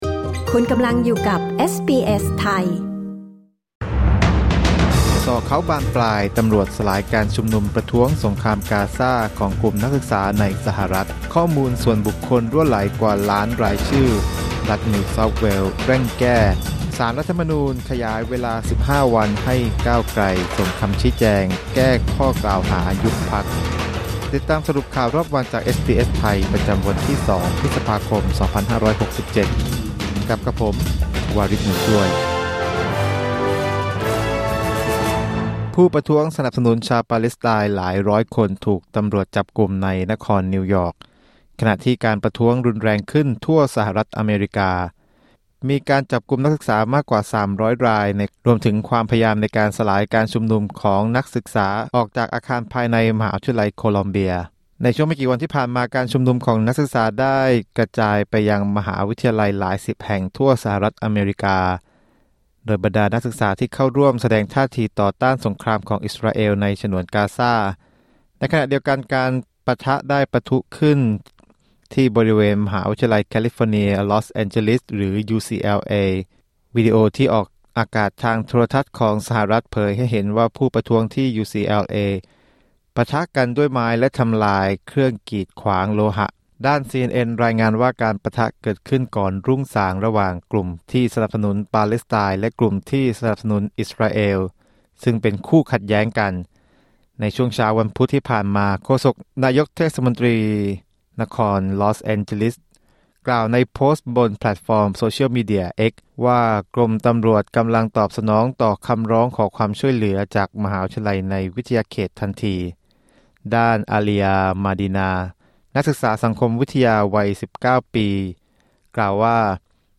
สรุปข่าวรอบวัน 2 พฤษภาคม 2567